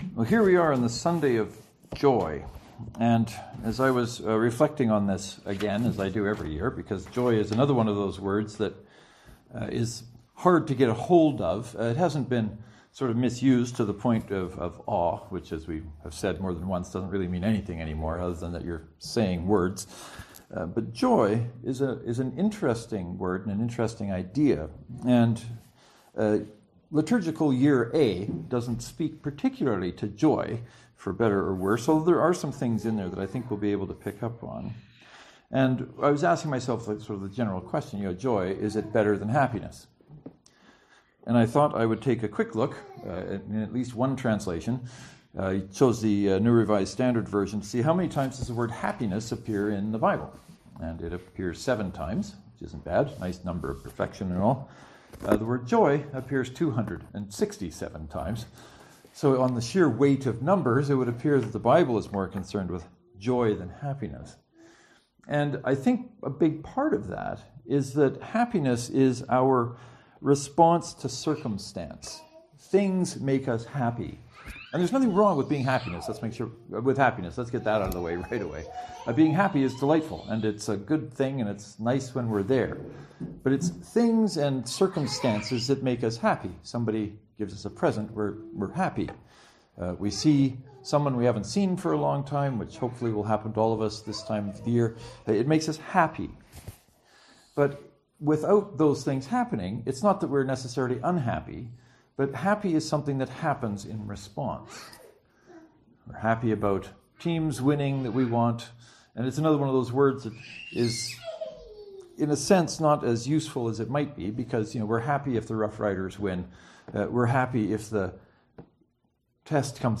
No matter where you find yourself in this discussion, I hope one or both of these sermons will be helpful to you in this season of Advent as you move into the season of Christmas.